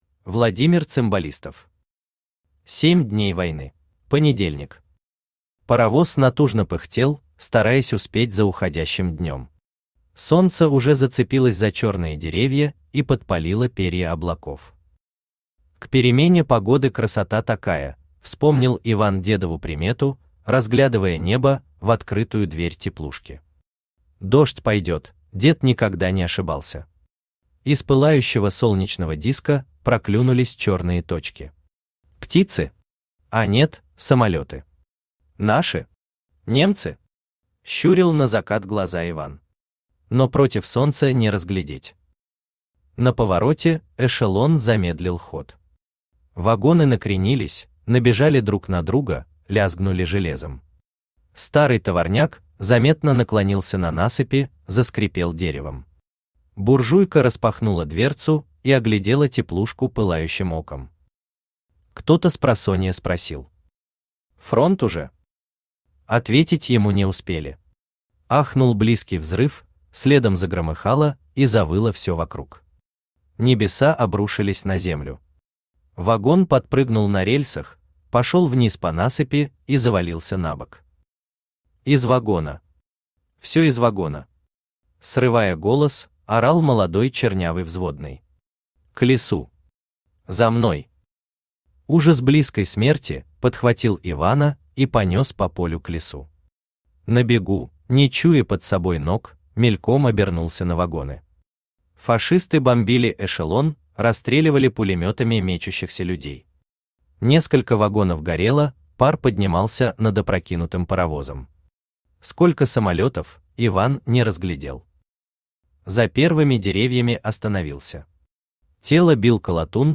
Аудиокнига Семь дней войны | Библиотека аудиокниг
Aудиокнига Семь дней войны Автор Владимир Виссарионович Цимбалистов Читает аудиокнигу Авточтец ЛитРес.